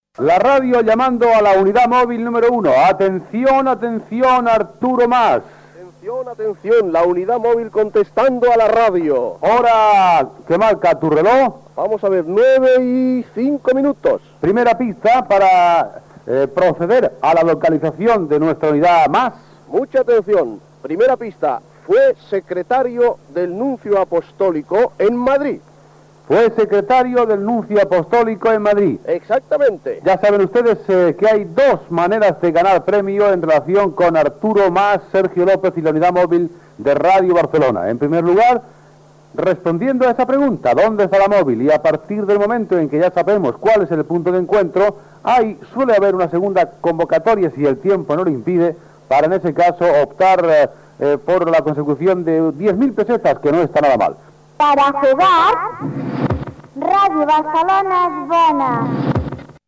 Secció "Dónde está la móvil?" . Pregunta del concurs i indicatiu del programa
Entreteniment